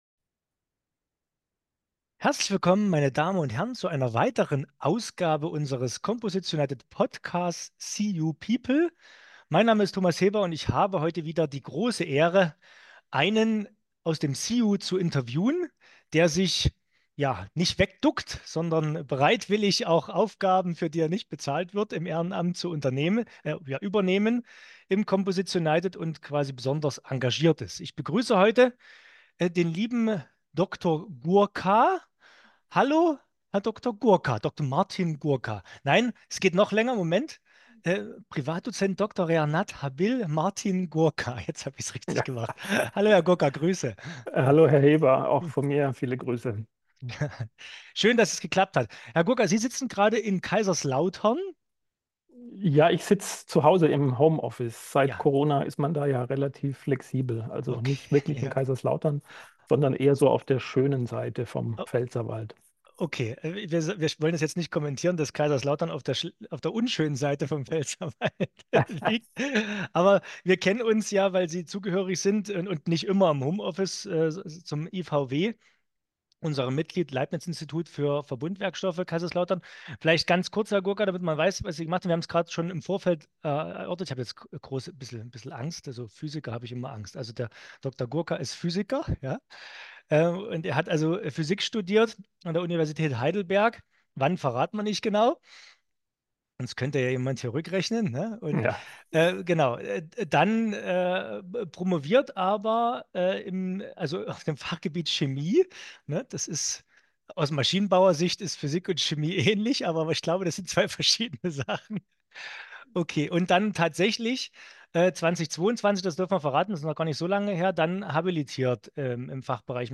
#43 Im Interview